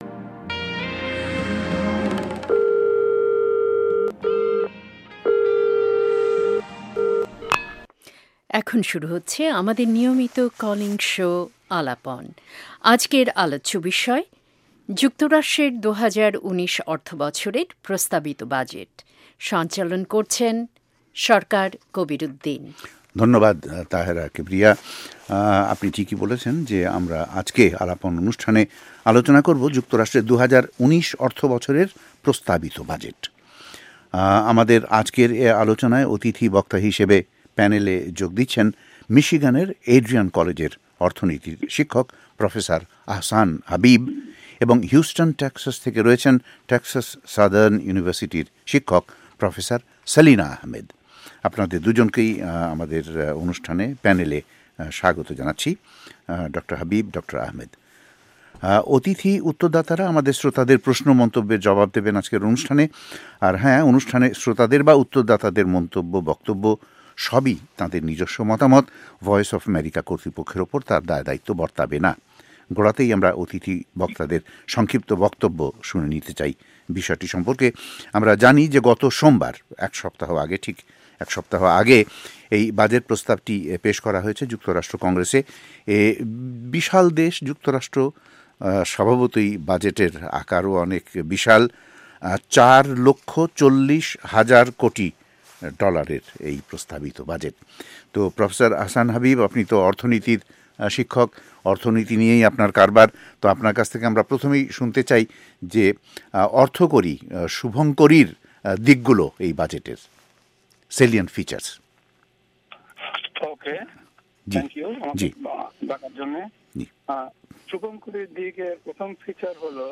টক শো আলাপন অনুষ্ঠান প্রচারিত হ’চ্ছে ভয়েস অফ এ্যামেরিকার ওয়াশিংটন স্টুডিও থেকে। আমরা আলোচনা ক’রছি ‘যুক্তরাষ্ট্র কংগ্রেসে উপস্থাপন করা দু’হাজার উনিশ অর্থ বছরের বাজেট প্রস্তাব নিয়ে ।